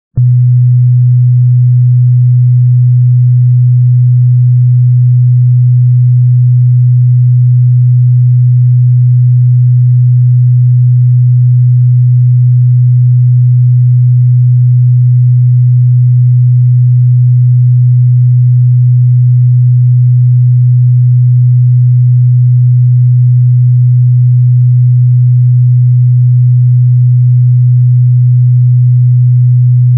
Several Guitar Tuning Files
30sec 123.75Hz Harmonic Alt 7th B string MP3 175K